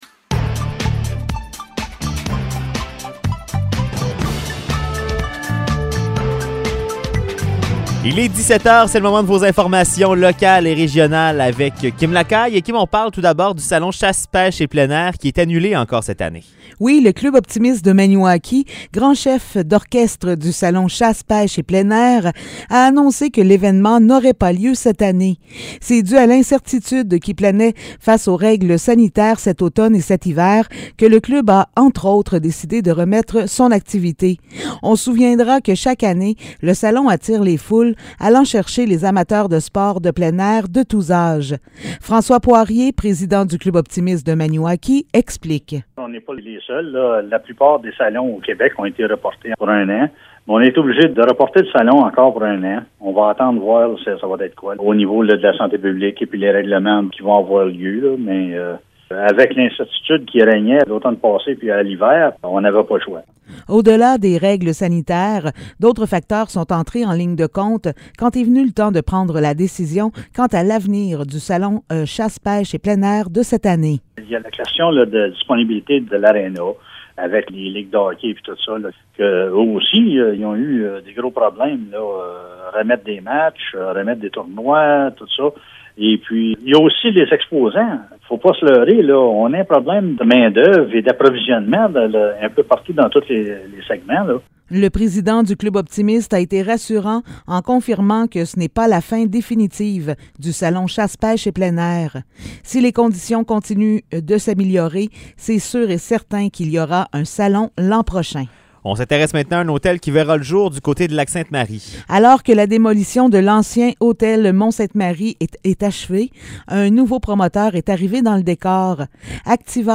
Nouvelles locales - 16 mars 2022 - 17 h